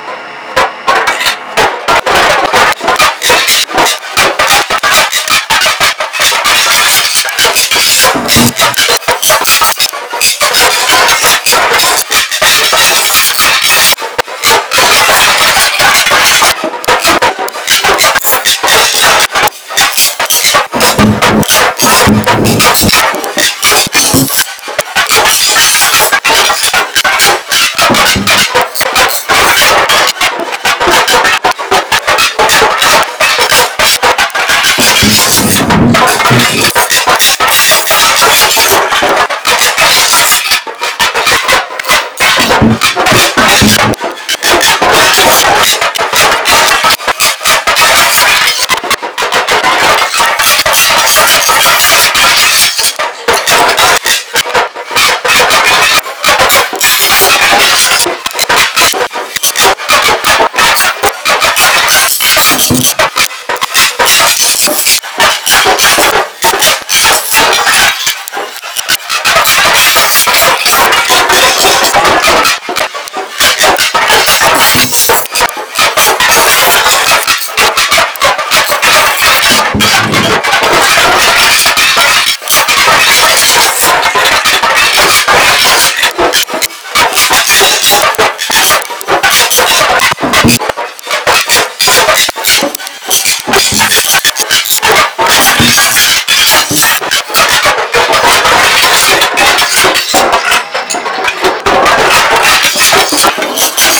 Granulator
I then experiment by playing on a midi and shifting the sample size.
Here are two examples of my original granulator experimentation.
granulator-original-2.wav